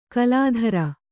♪ kalādhara